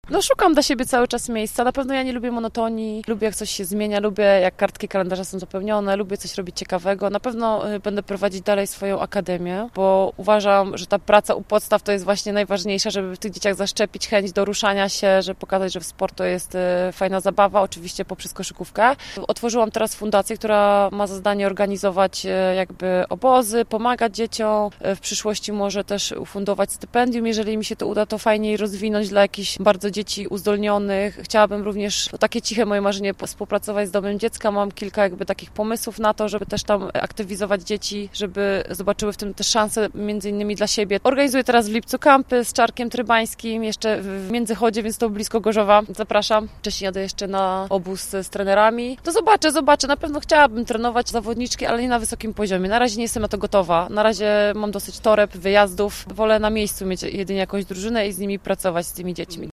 Wychowanka Stilonu Gorzów i była już zawodniczka AZS AJP mówi, że ma sporo pomysłów na życie po karierze: